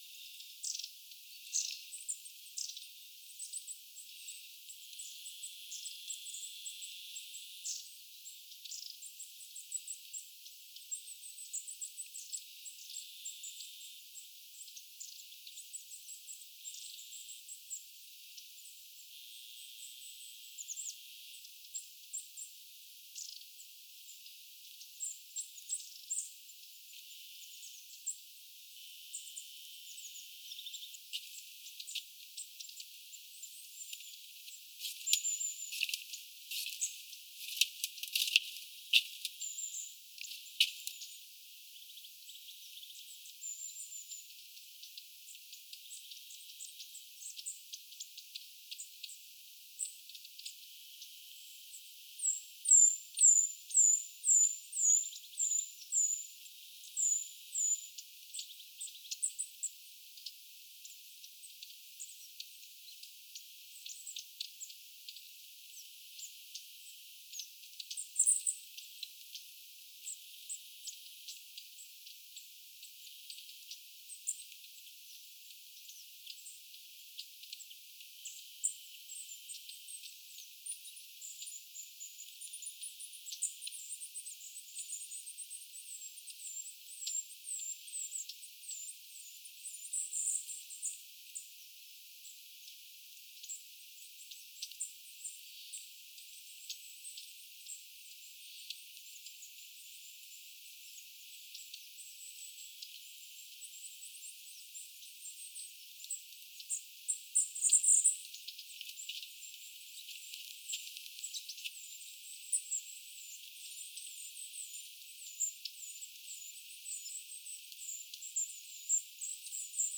pyrstötiaisia, tiaisparvi
Ihmettelen sitä, että vaikka parvessa ei ole hippiäisiä,
kuuluu parvesta hippiäismäisiä ääniä.
pyrstotiaisparvi_tiaisparvi.mp3